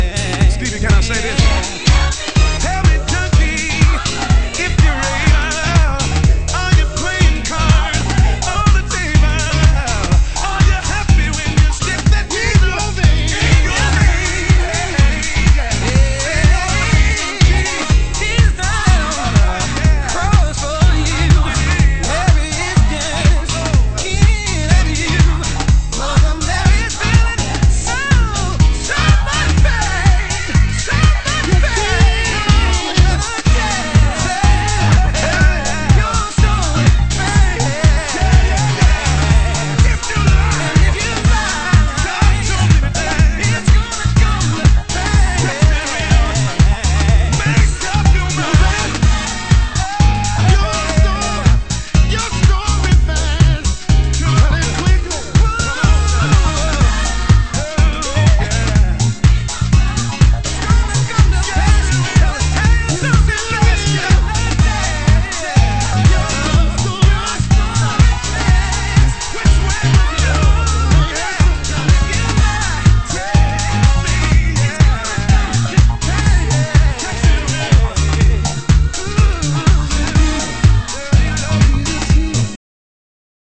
(Vocal Mix)